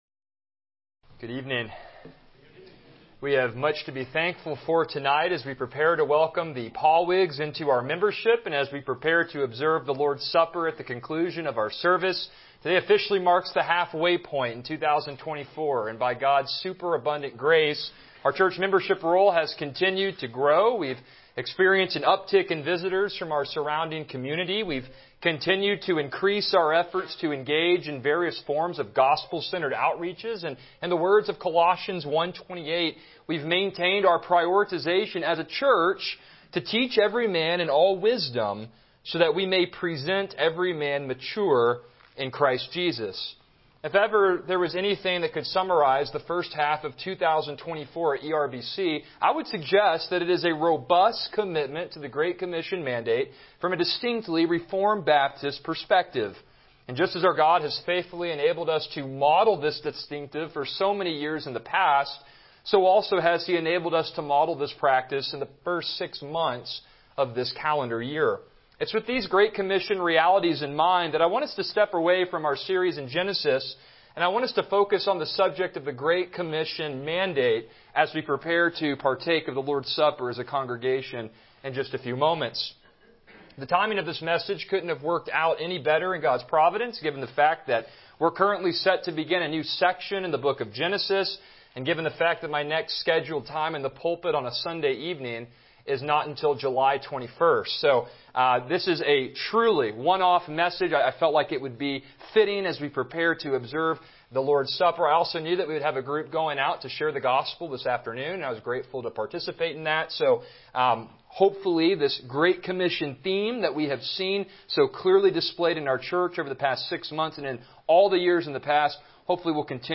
Passage: Matthew 28:16-20 Service Type: Evening Worship